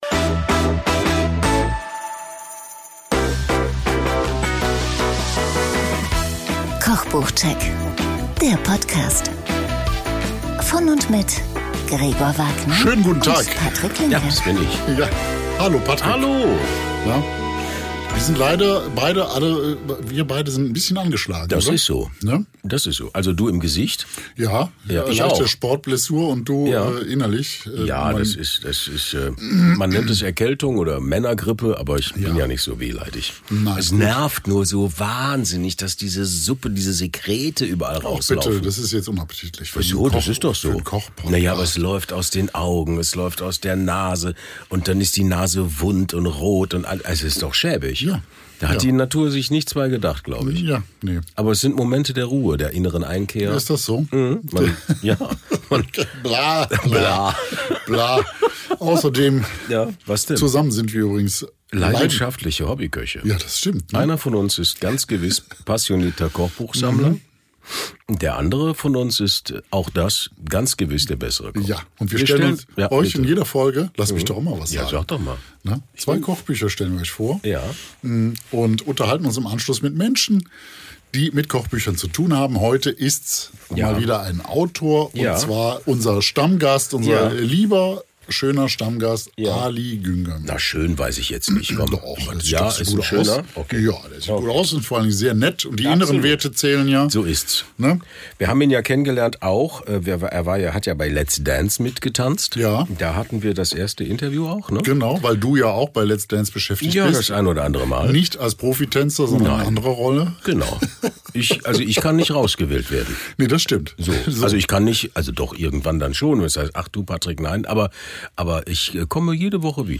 Das erklärt uns Ali im anschließenden Interview sehr genau.